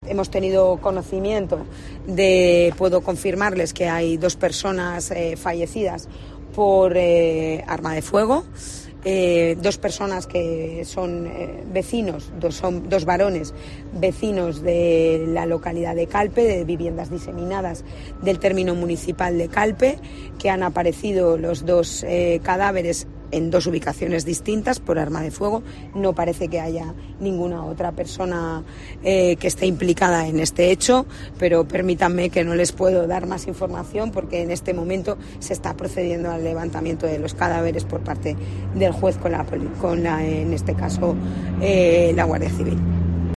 La delegada del Gobierno, Pilar Bernabé, hace una primera valoración del trágico suceso en Calpe